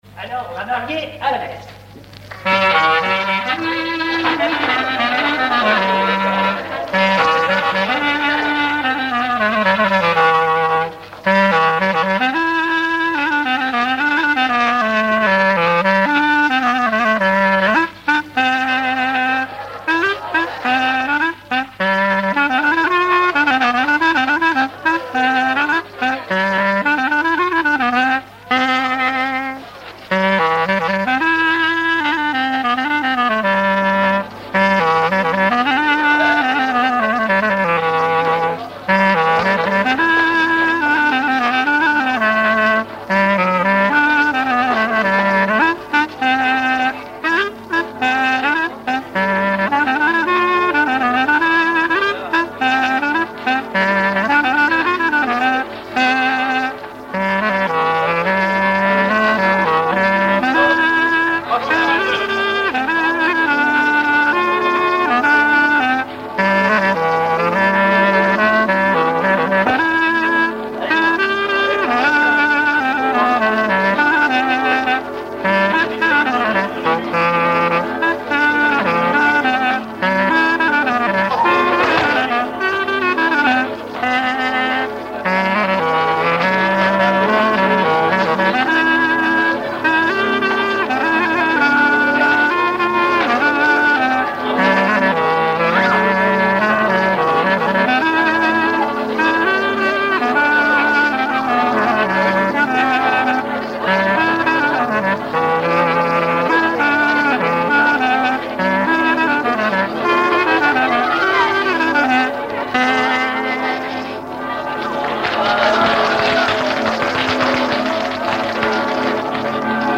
Fonction d'après l'informateur gestuel : à marcher
Usage d'après l'informateur circonstance : fiançaille, noce
Genre brève
Catégorie Pièce musicale inédite